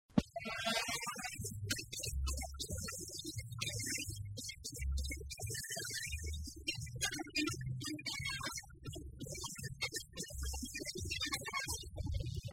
jingle 7 .